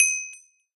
should be correct audio levels.
orb.ogg